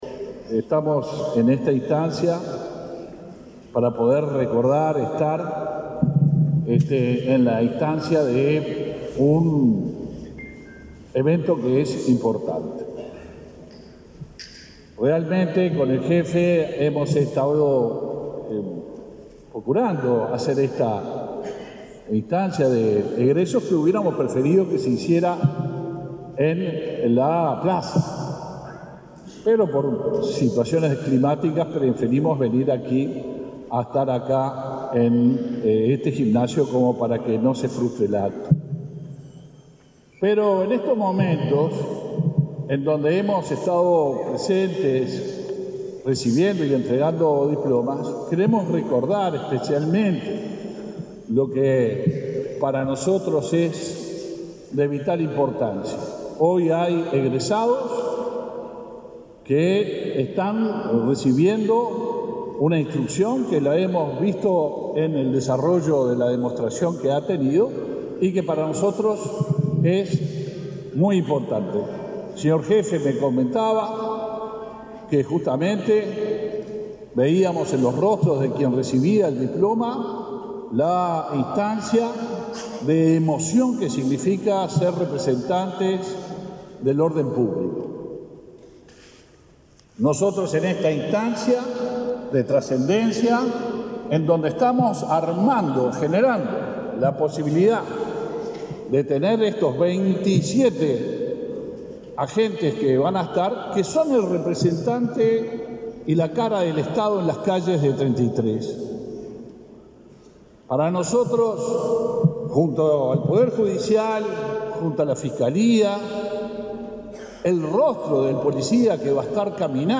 Palabras del ministro del Interior, Luis Alberto Heber
El ministro del Interior, Luis Alberto Heber, visitó el departamento de Treinta y Tres, donde concurrió a la Jefatura departamental y a la Comisaría